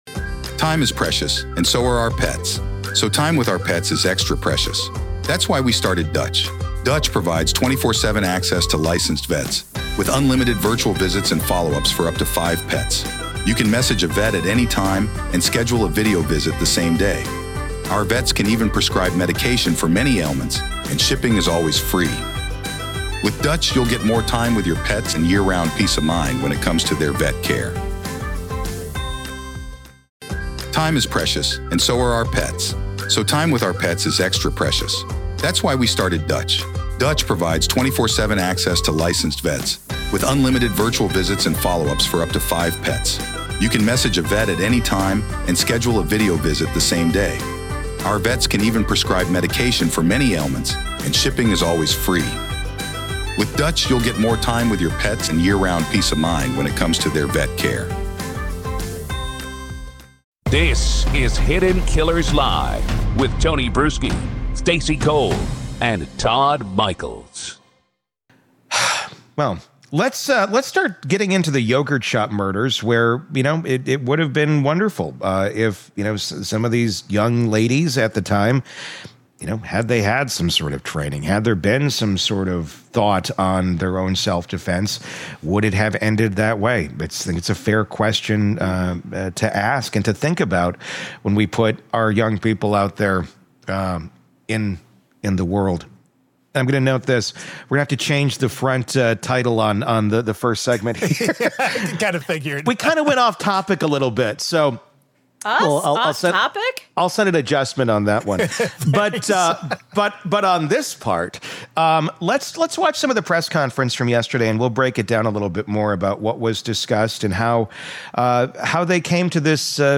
We play back the portion of the press conference where Travis County DA Jose Garza publicly acknowledges their innocence —and we react to the gravity of what that admission means.